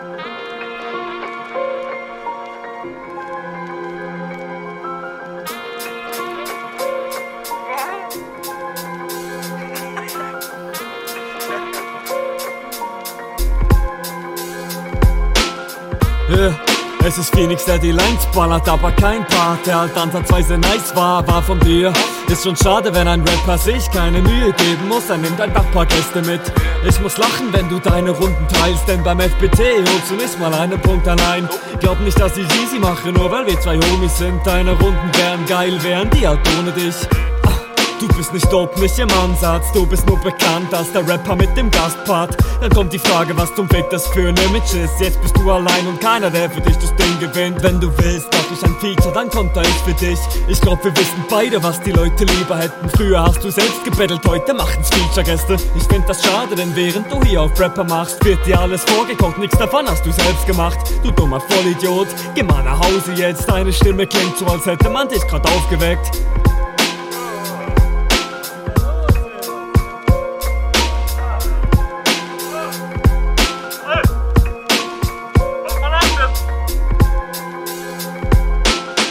Der Flow ist noch sehr ungereift, der Einstieg wirkt ein wenig off aber die Runde …